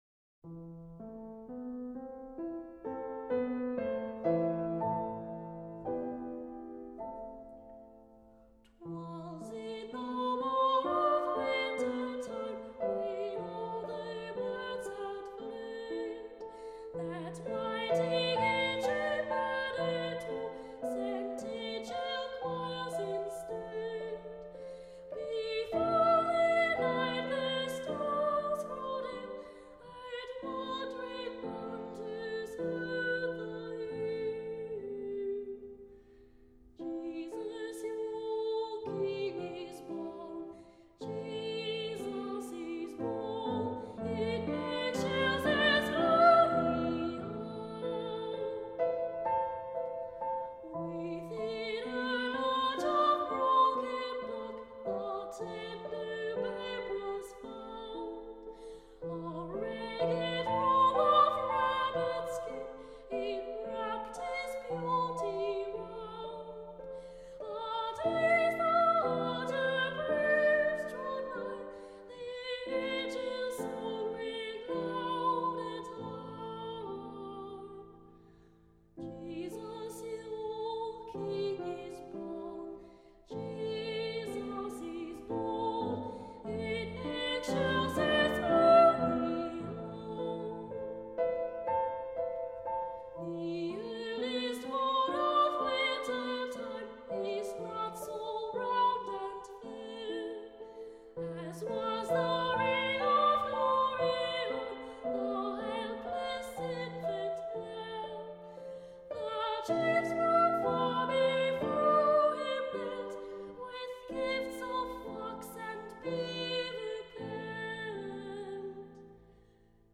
Diese Aufnahme mit einem kanadischen(!) Wiener Sängerknaben entstand 1999.
This recording with a Canadian(!) member of the Vienna Boys Choir was made in 1999.